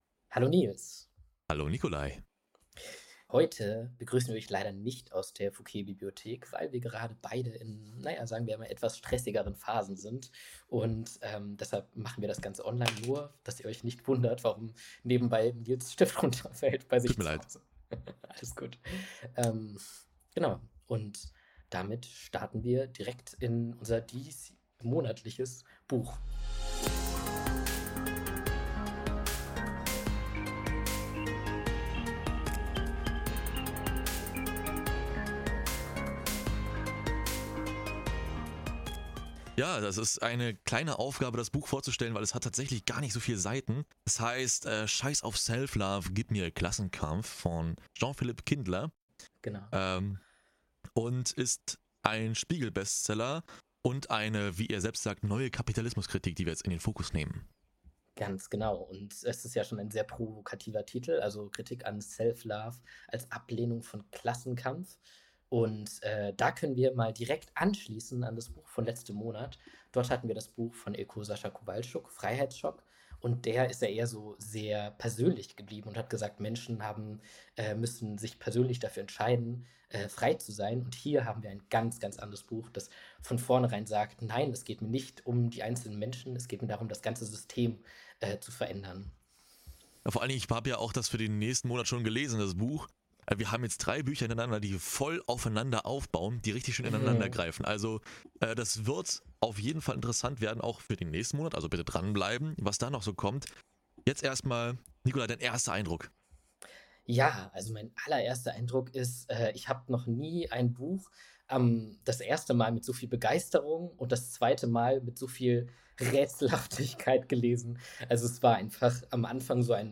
Freu dich auf einen leidenschaftlichen Austausch, der die Frage nach der Bedeutung des Klassenkampfes im heutigen Diskurs stellt.